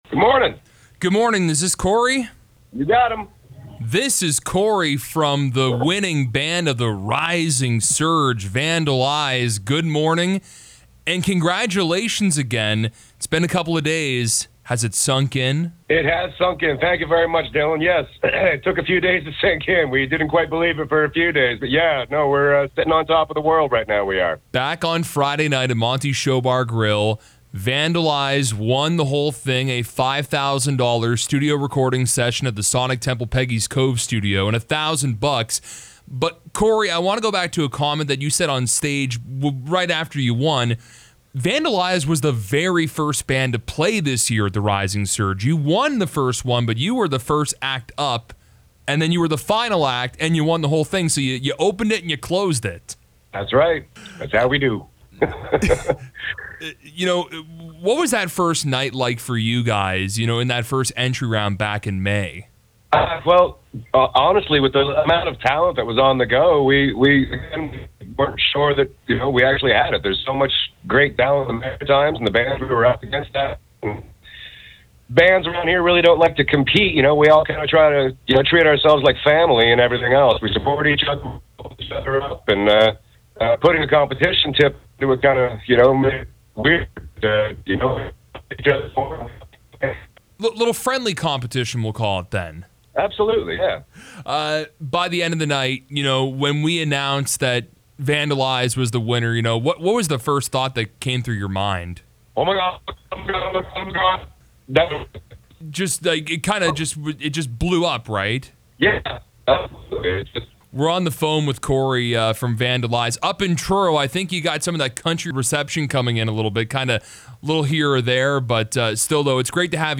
vandal-eyes-chat.mp3